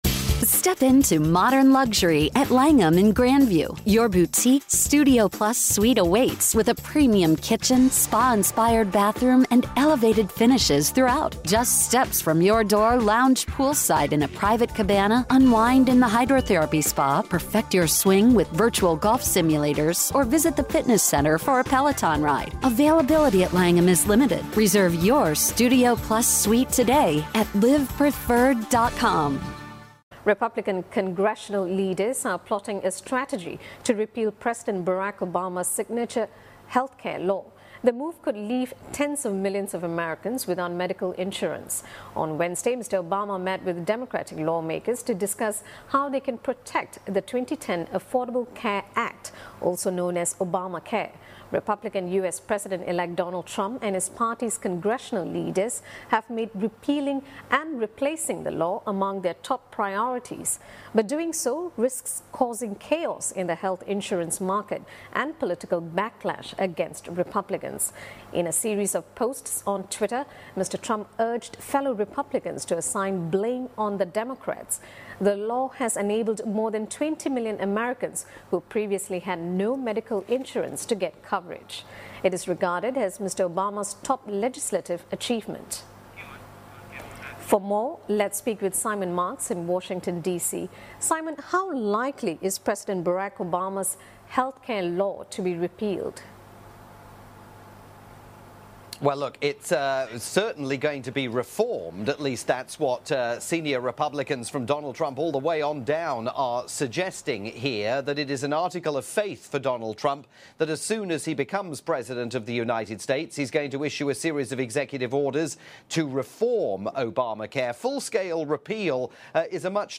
report aired on pan-Asian TV news network Channel NewsAsia